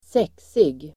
Uttal: [²s'ek:sig]